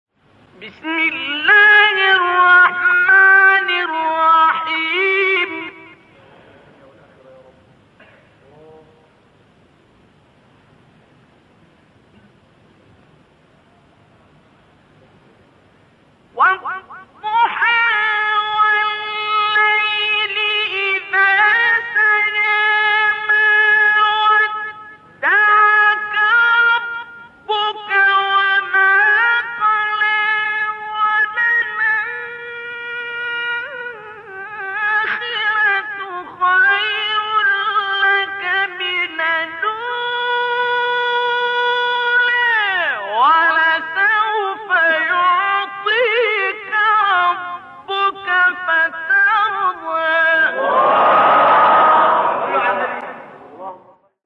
آیه 1-5 سوره ضحی استاد عبدالباسط | نغمات قرآن | دانلود تلاوت قرآن